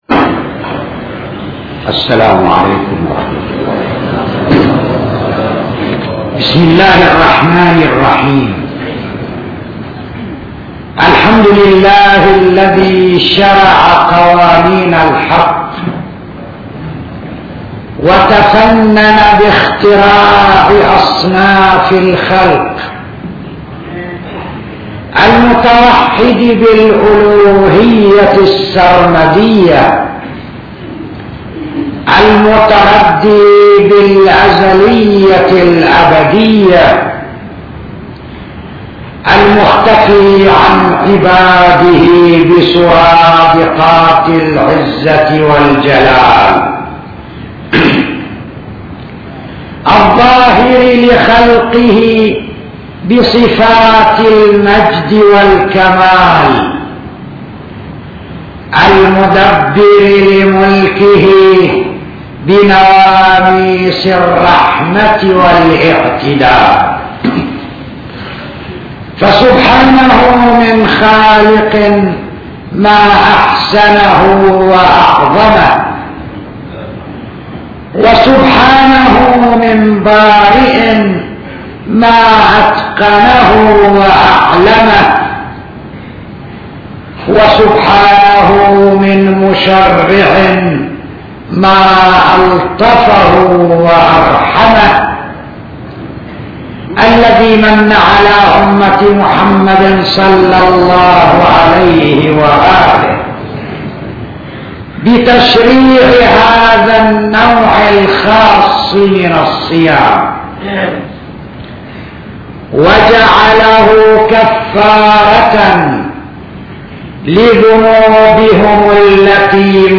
خطب